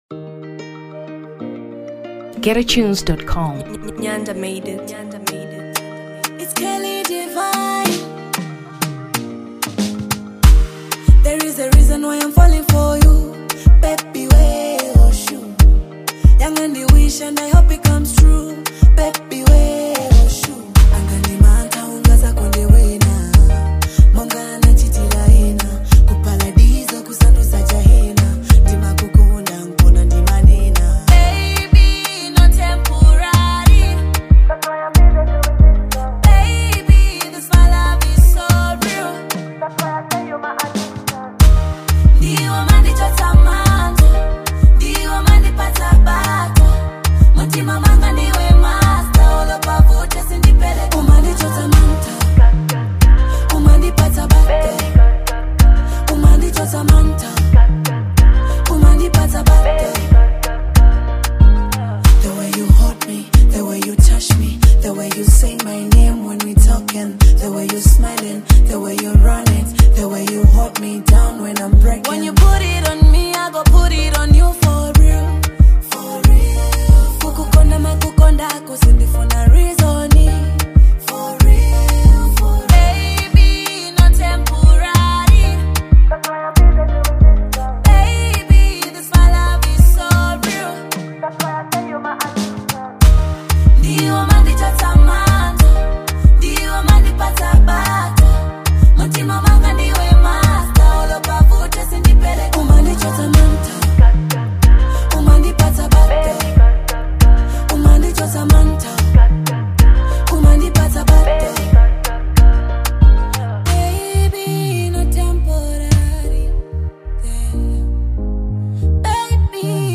Afro Pop 2023 Malawi